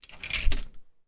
household
Cloth Hanger Movement 3